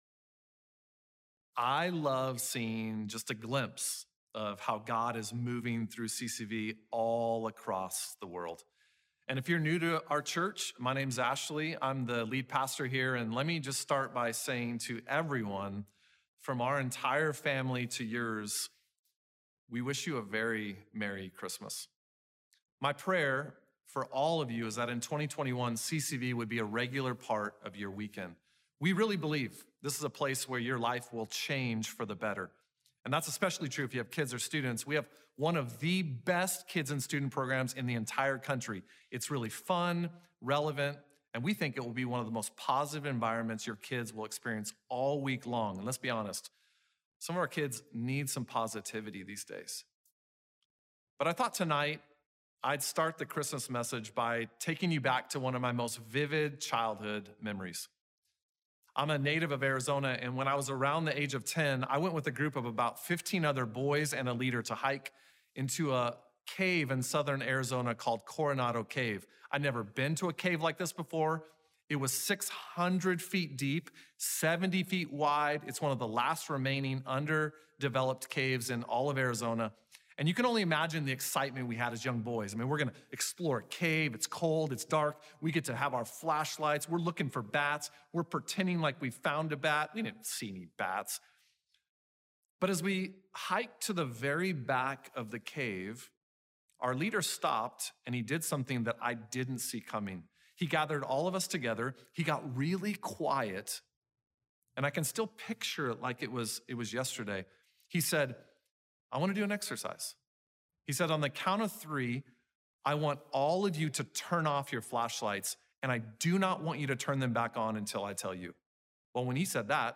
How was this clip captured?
We're setting up entirely outside all week long to provide Christmas evening services across the Valley.